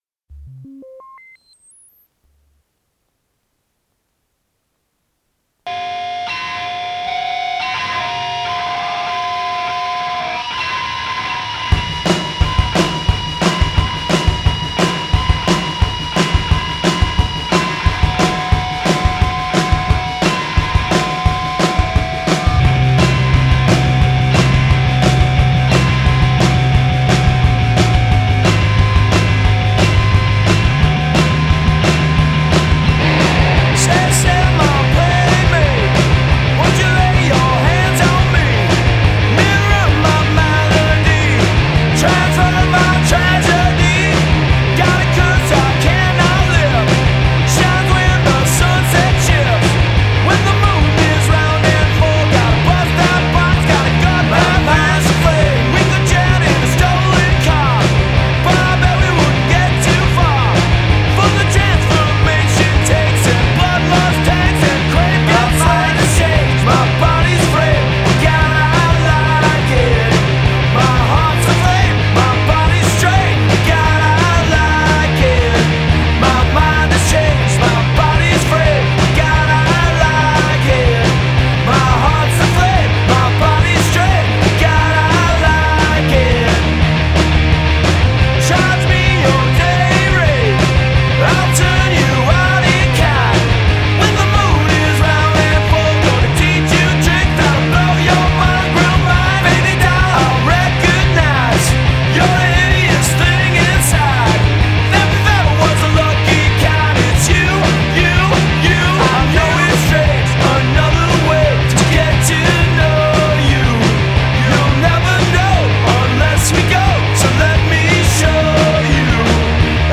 two-piece